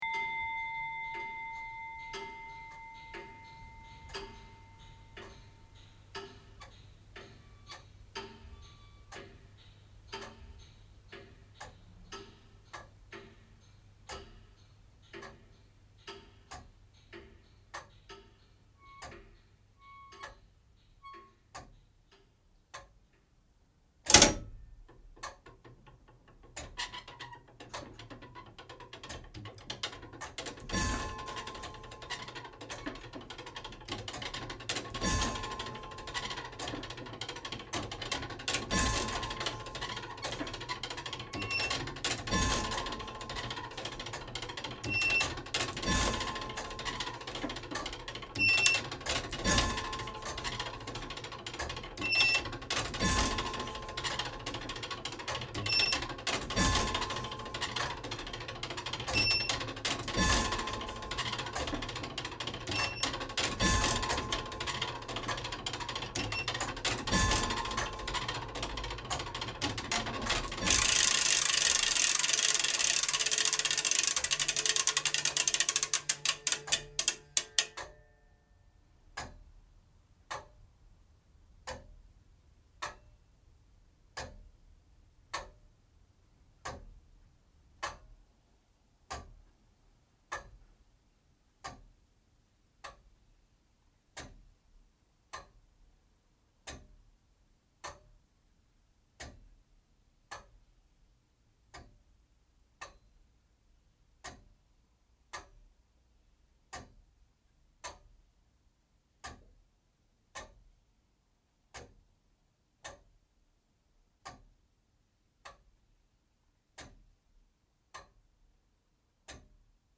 Blickling Hall – Clock Tower
13-Blickling-Hall-Clock-Tower.mp3